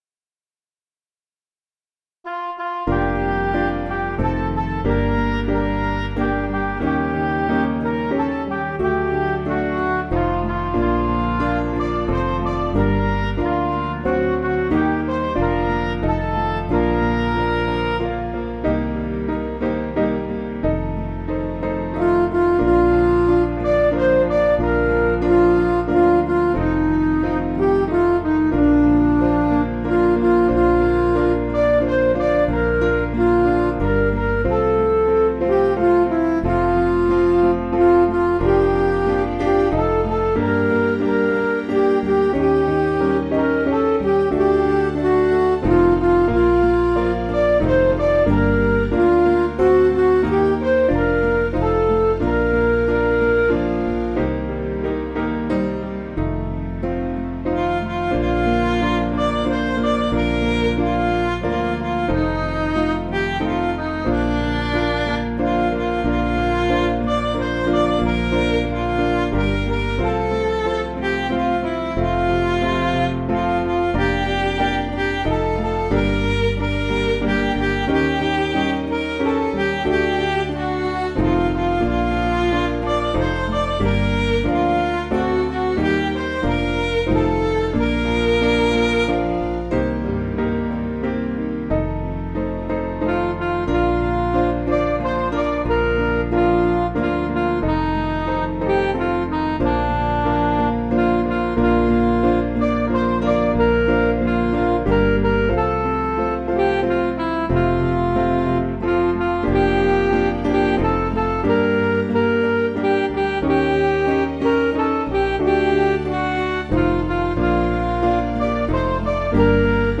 (CM)   4/Bb
Midi